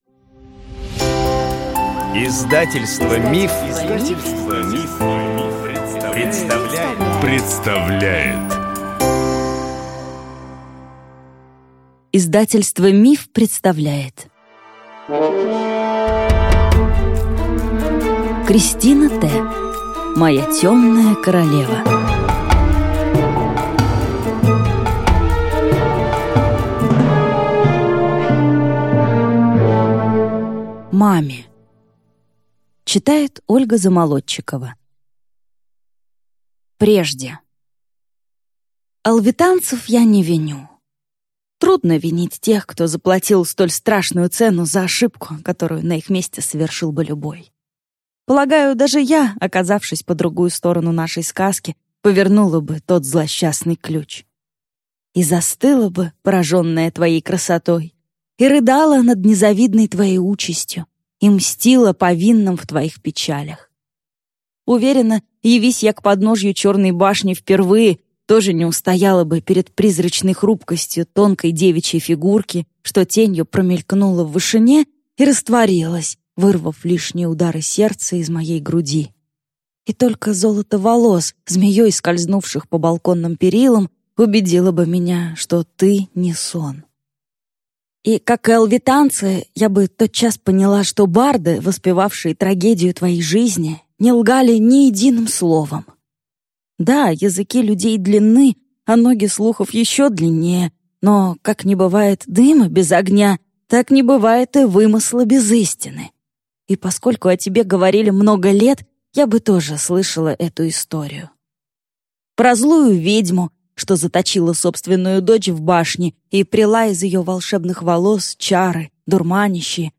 Аудиокнига Моя темная королева | Библиотека аудиокниг